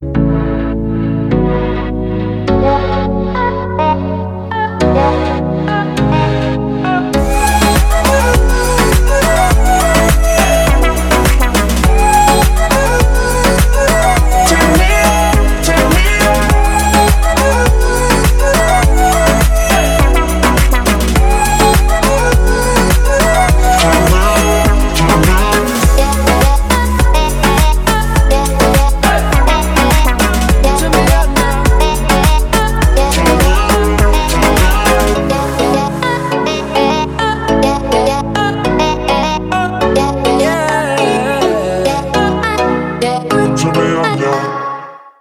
• Качество: 320, Stereo
Стиль: moombahton